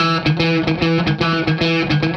AM_HeroGuitar_110-E01.wav